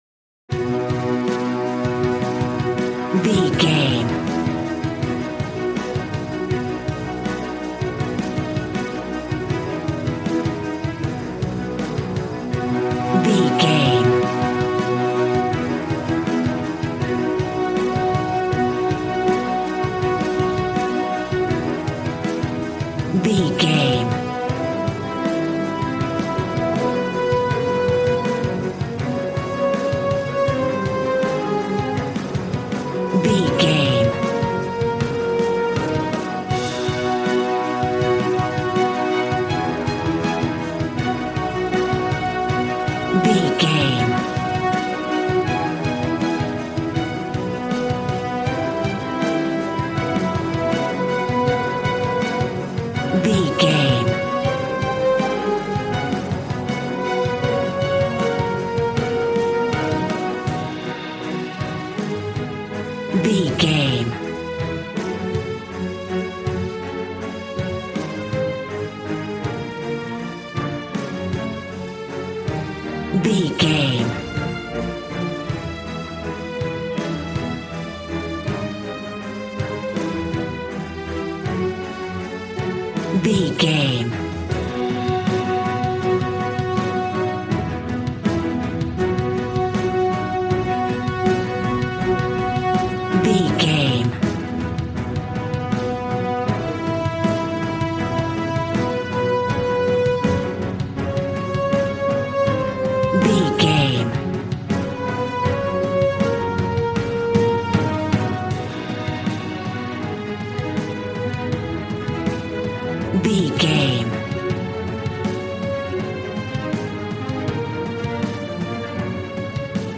Ionian/Major
dramatic
epic
strings
violin
brass